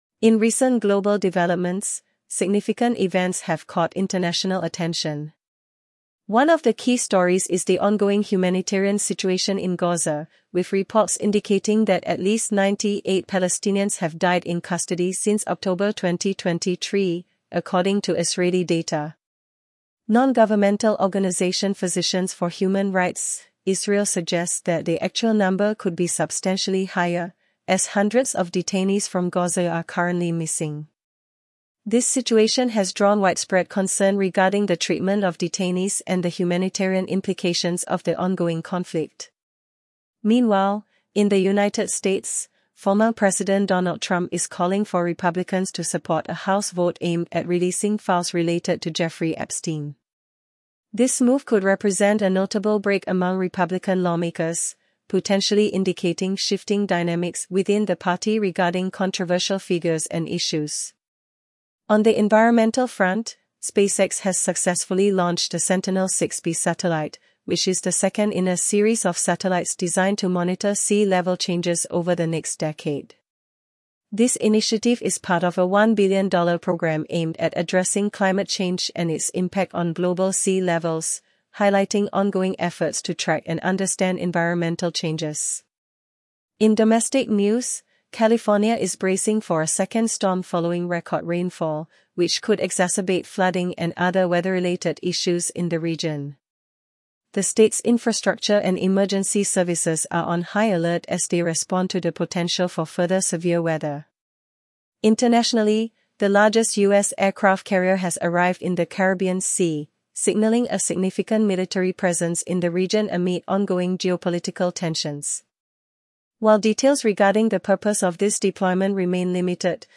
World News Summary
World News